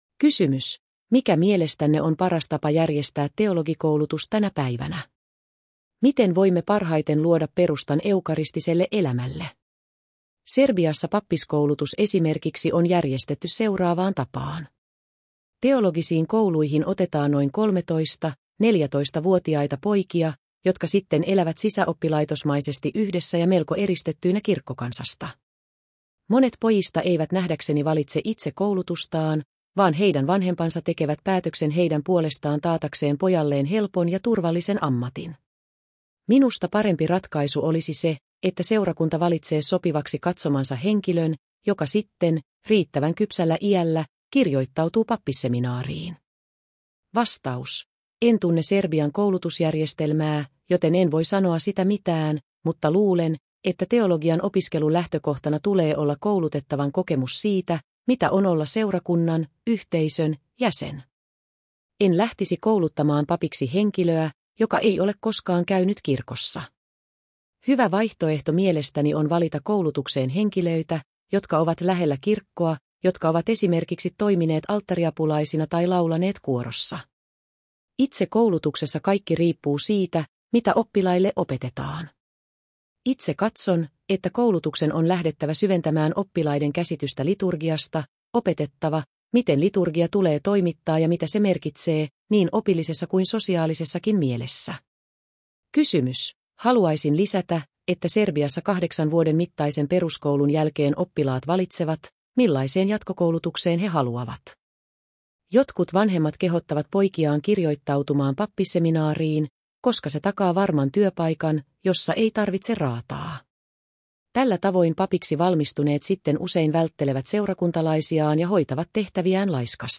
Mp. Johannes Zizioulasin haastattelu osa 2